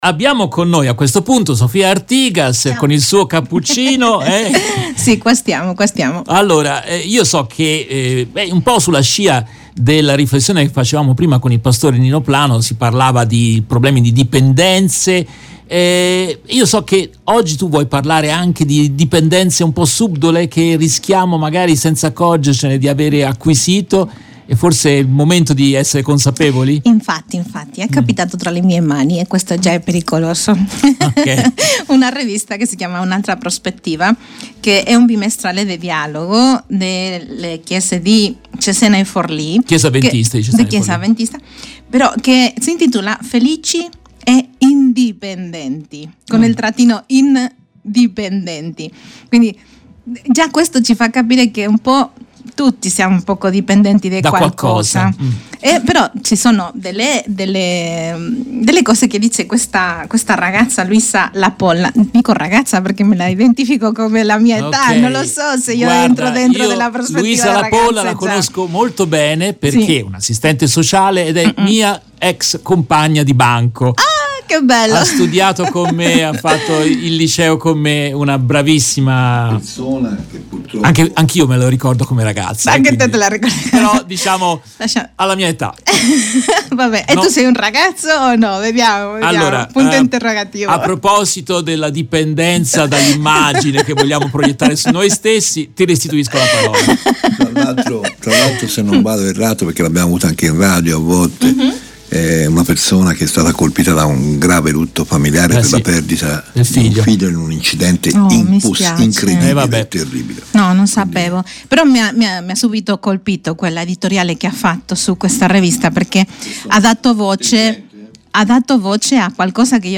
intervistata